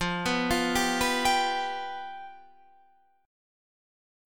F Suspended 2nd Flat 5th